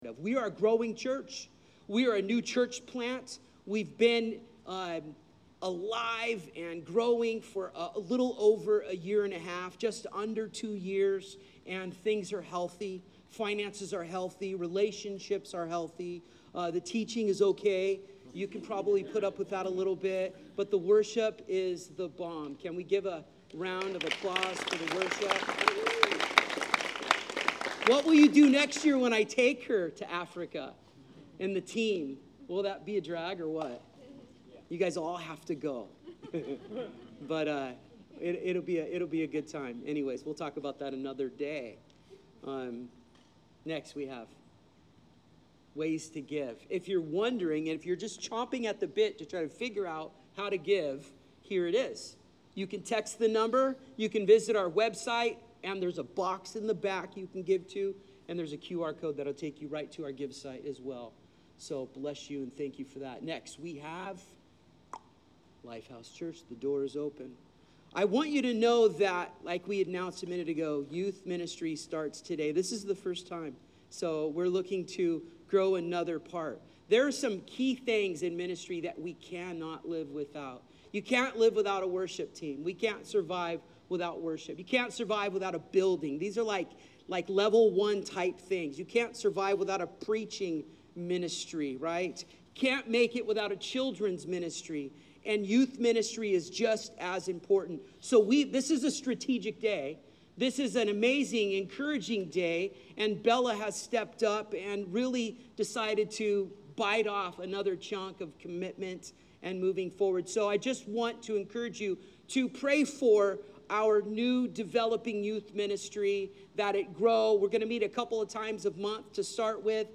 Zambia Mission Recap - Special interview — LifeHouse Church